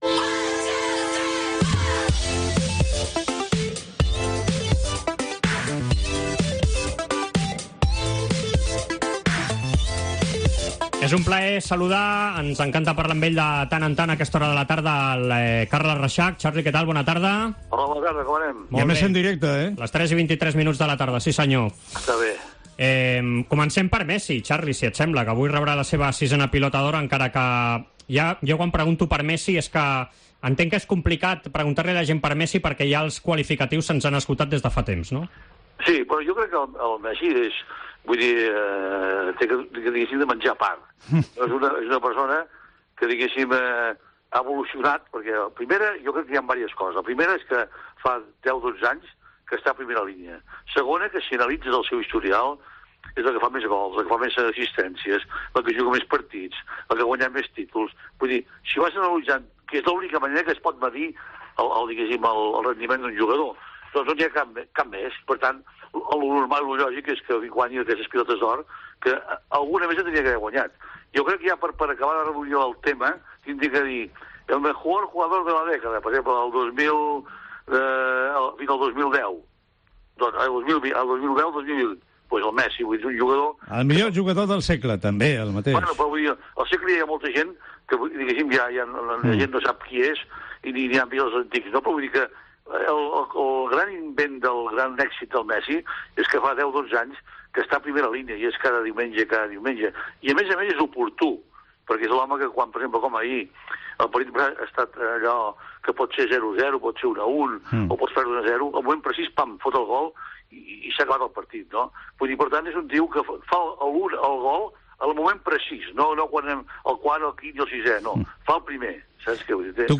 El hombre que firmó el primer contrato de Leo Messi con el Barça en una servilleta de papel, Carles Rexach , ha destacado la figura del argentino en una entrevista concedida a Esports Cope “Messi come aparte.